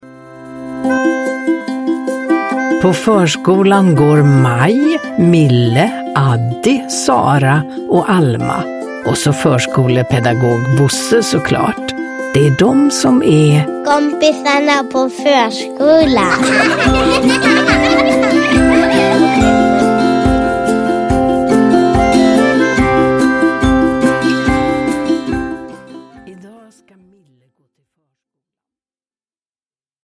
Mille går till förskolan – Ljudbok
Uppläsare: Ulla Skoog